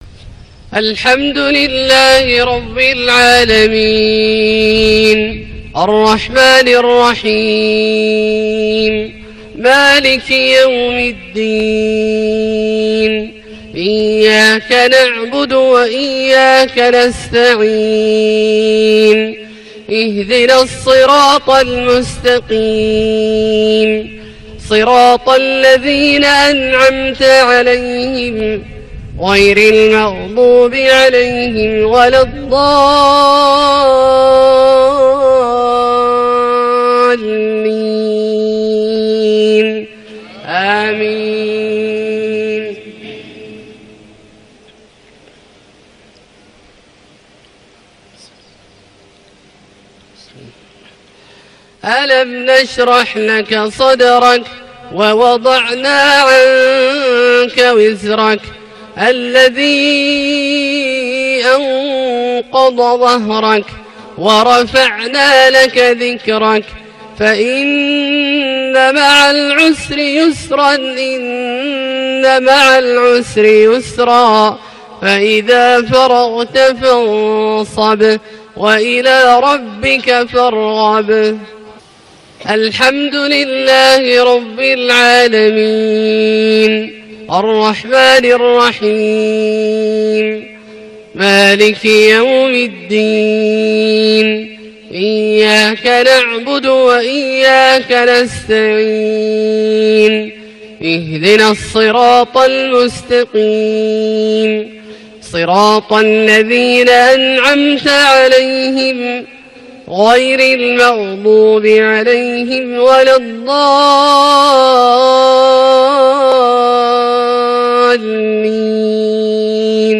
Maghrib prayer 2-4-2017 surah AshSharh & AnNasr > 1438 H > Prayers - Abdullah Al-Juhani Recitations